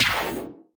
death_2.ogg